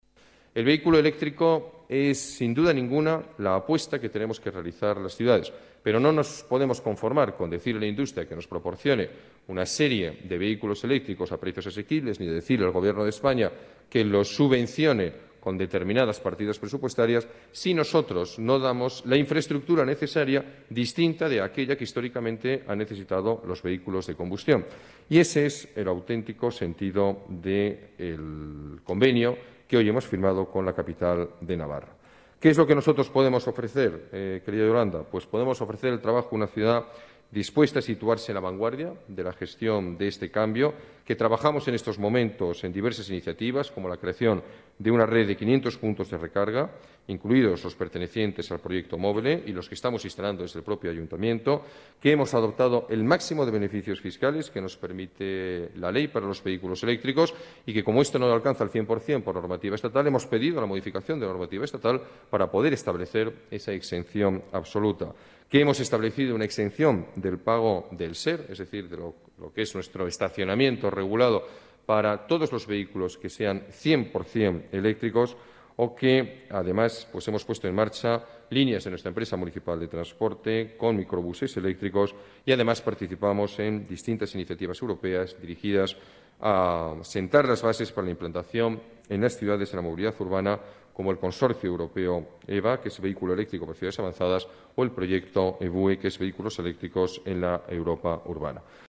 Nueva ventana:Declaraciones del alcalde de la Ciudad de Madrid, Alberto Ruiz-Gallardón: Reto urbano